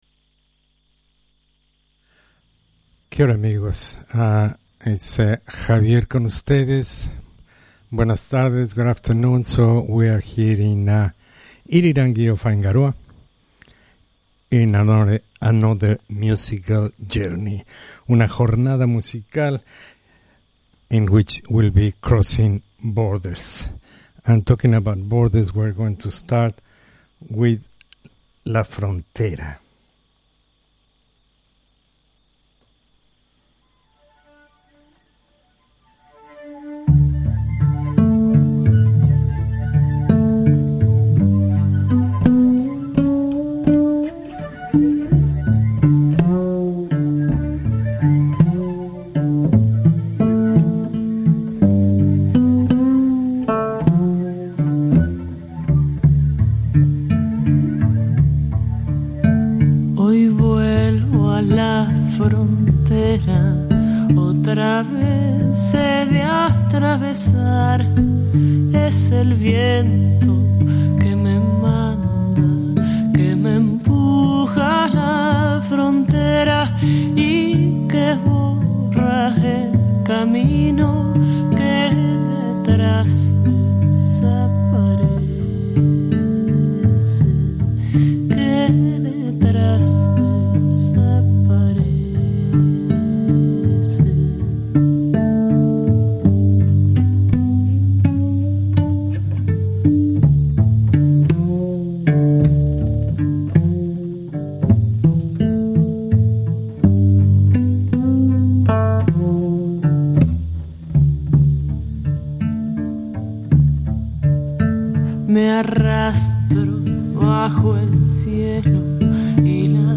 Cruzando Fronteras Music written in a Latin American Country interpreted by musicians of another, all in the Spanish language.